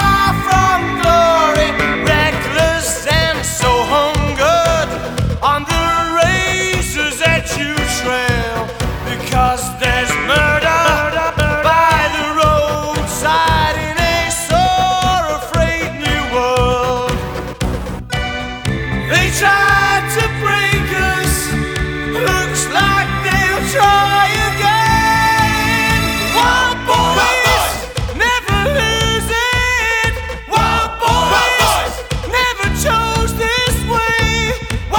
# New Wave